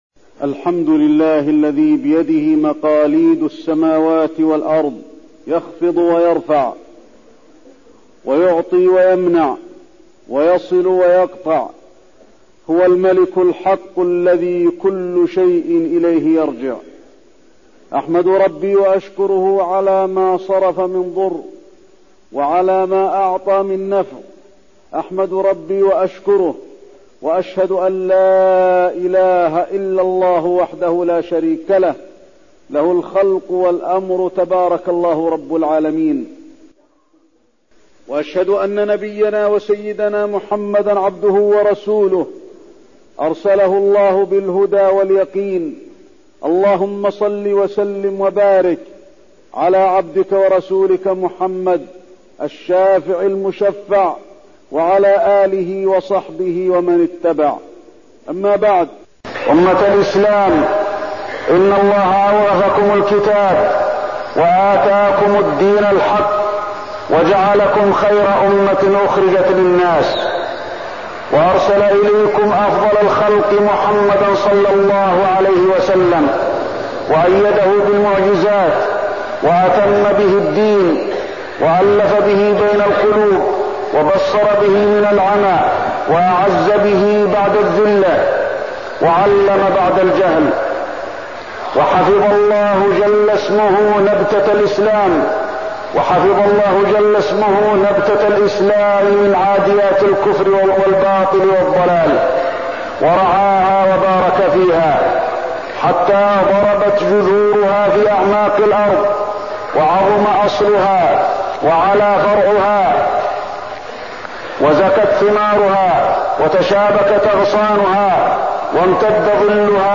تاريخ النشر ١٠ ذو الحجة ١٤١٤ هـ المكان: المسجد النبوي الشيخ: فضيلة الشيخ د. علي بن عبدالرحمن الحذيفي فضيلة الشيخ د. علي بن عبدالرحمن الحذيفي دعوة النبي صلى الله عليه وسلم وفضل يوم عرفة